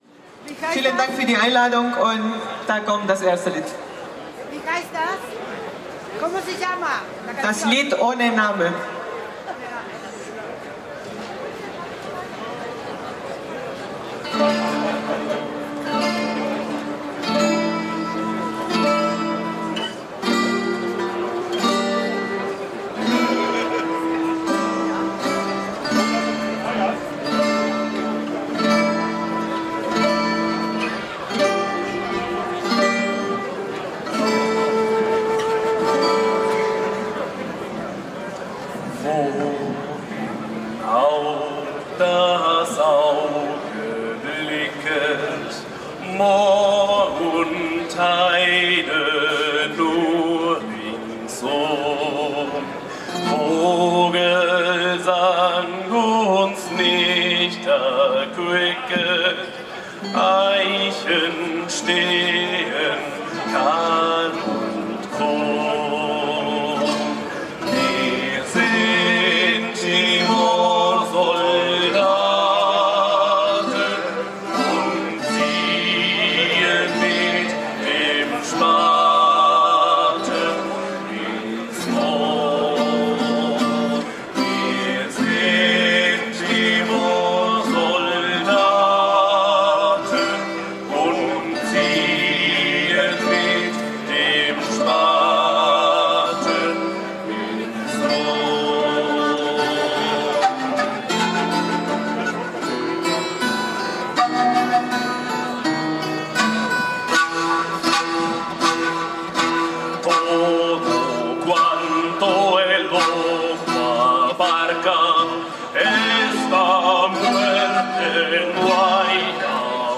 Eindrücke der lateinamerikanisch/europäisch geprägten musikalischen Darbietung von „Musikandes“[7, 8] auf dem Marktplatz[9] in der Düsseldorfer Altstadt.
Die Kundgebung wurde musikalisch untermalt von „Musikandes“